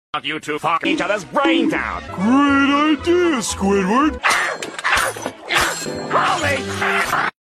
🧽 Follow for more deep fried sound effects free download